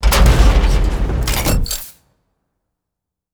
clamp.wav